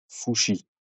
Fouchy (French pronunciation: [fuʃi]